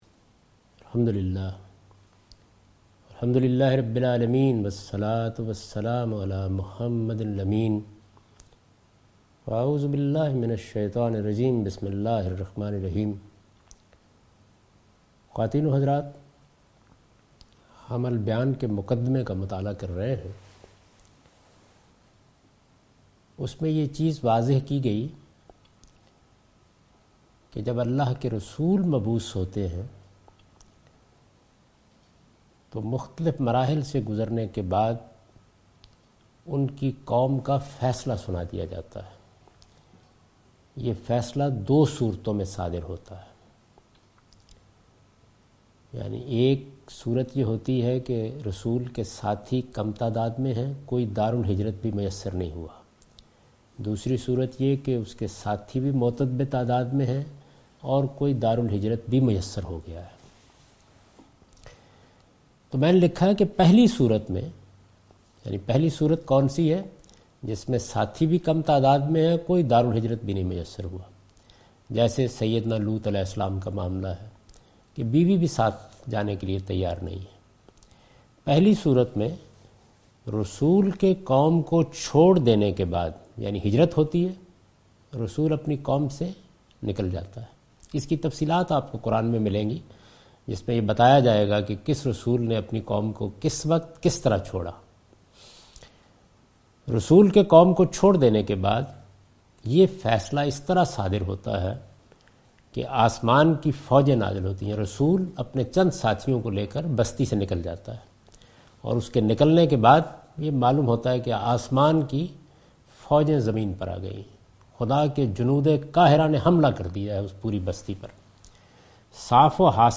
A comprehensive course on Quran, wherein Javed Ahmad Ghamidi teaches his tafseer ‘Al Bayan’.